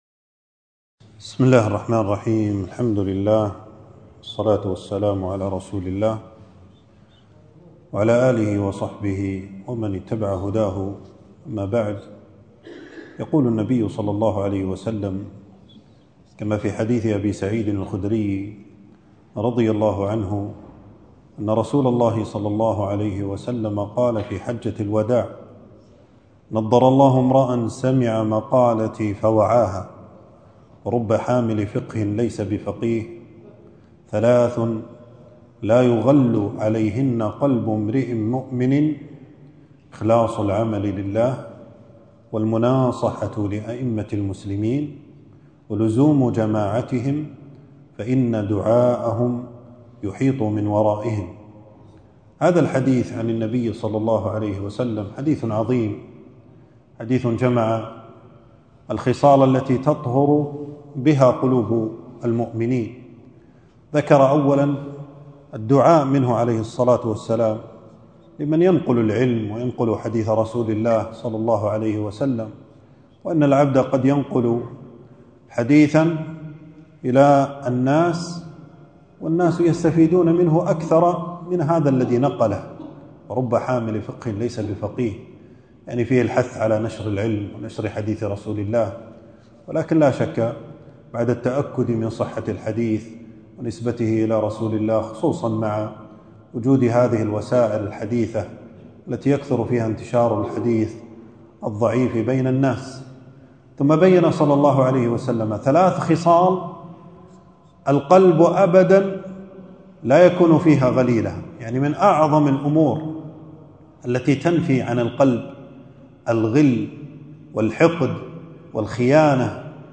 في مسجد أبي سلمة بن عبدالرحمن.